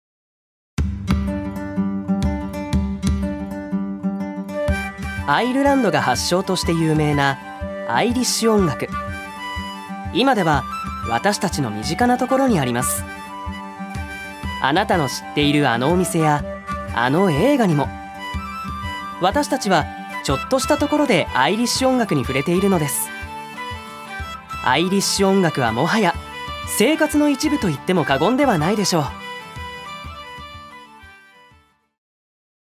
所属：男性タレント
ナレーション１